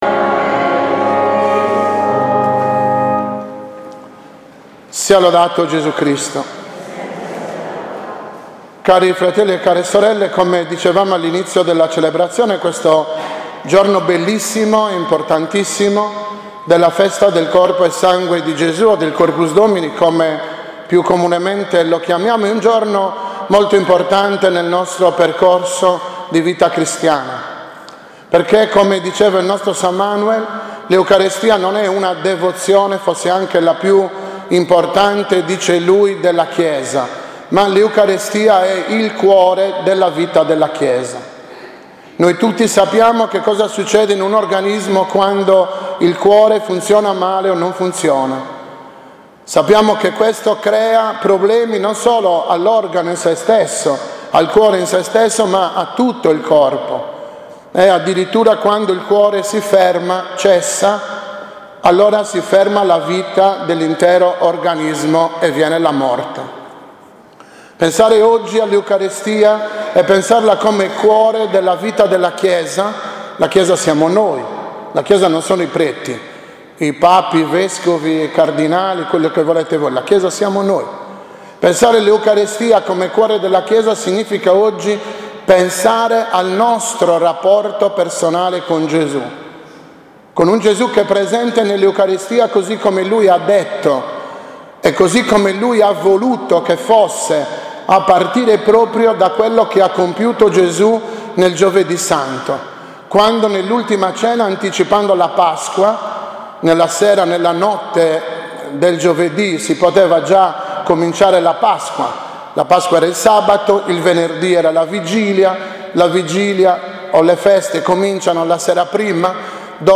OMELIA-DEL-CORPUS-2019.mp3